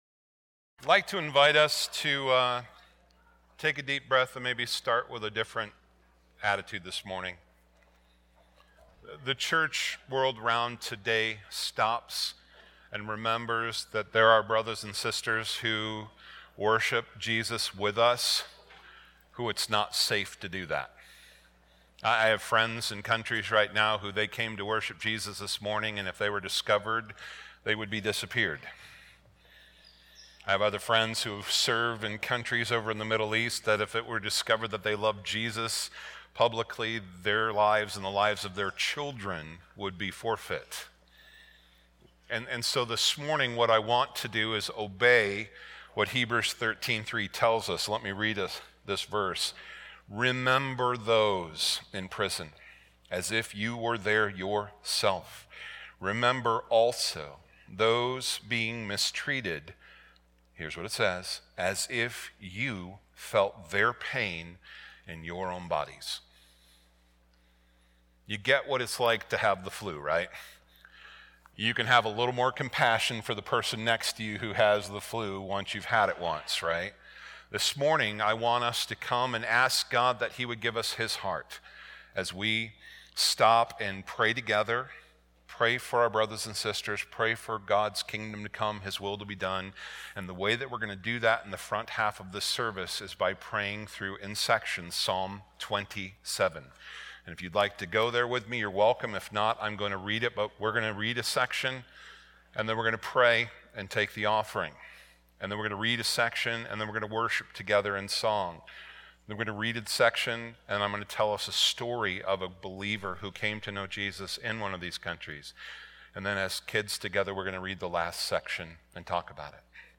Weekly Sermons - Evangelical Free Church of Windsor, CO